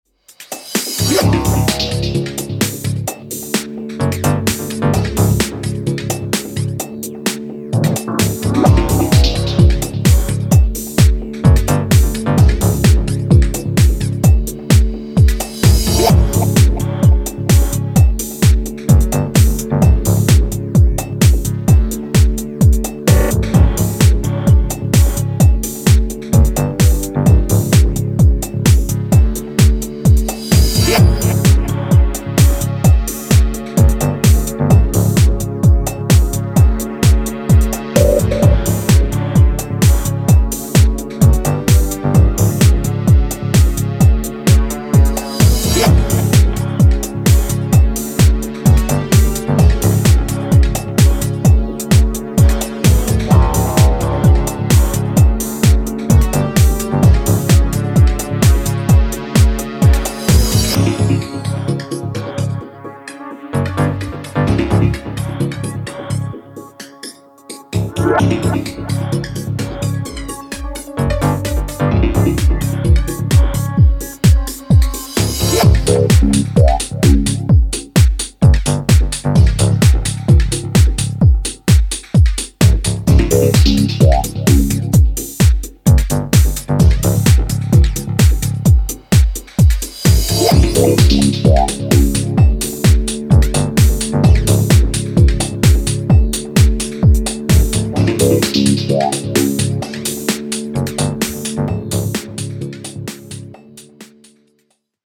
dark basements and gritty sounds
Electro House Trance